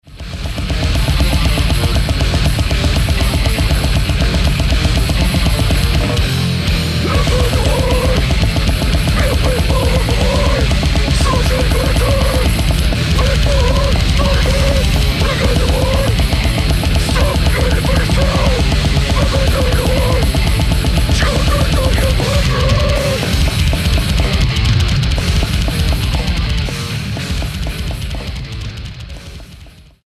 唸る咆哮! 暴虐の重音!!